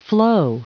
Prononciation du mot floe en anglais (fichier audio)
floe.wav